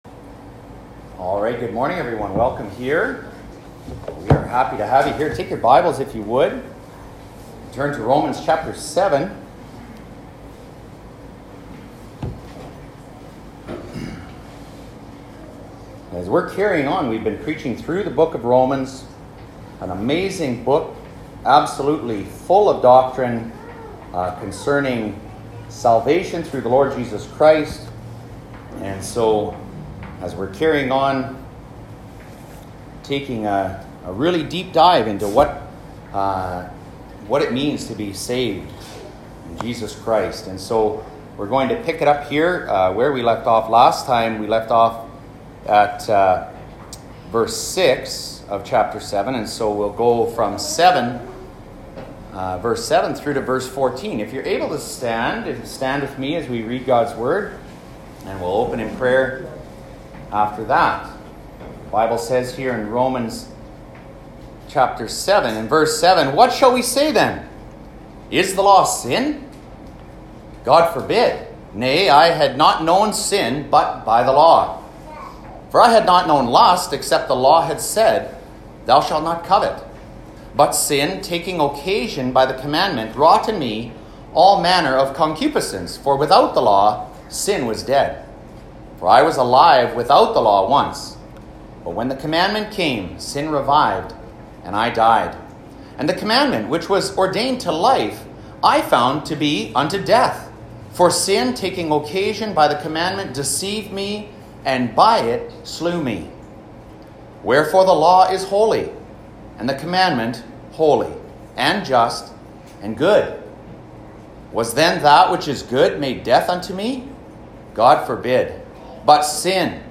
Sermons | Harvest Baptist Church